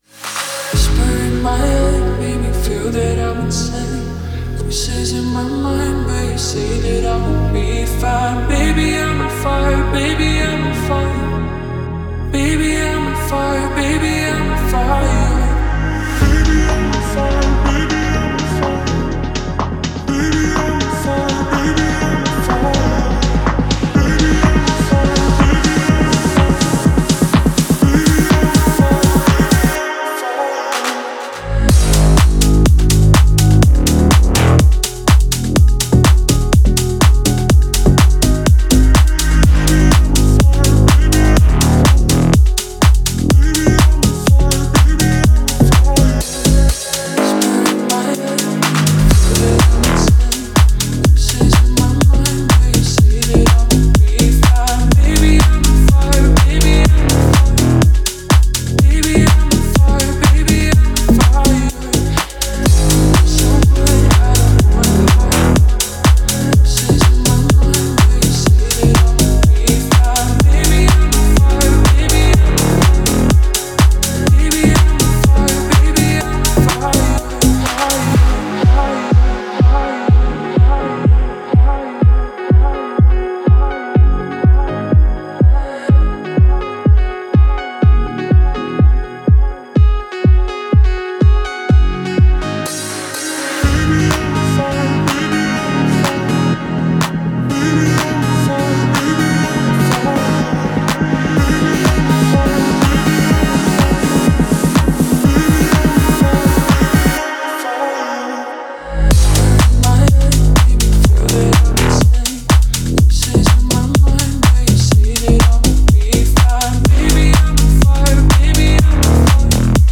энергичная композиция